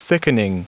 Prononciation du mot thickening en anglais (fichier audio)